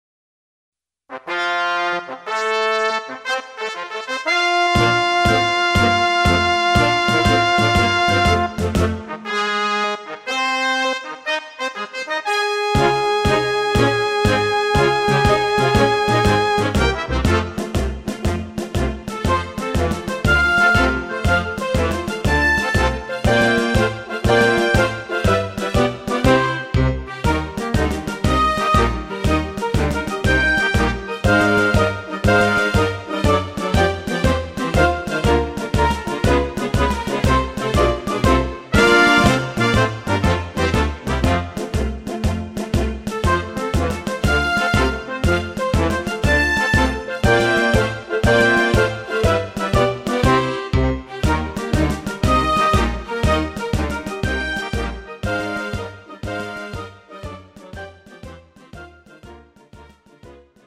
장르 뮤지컬 구분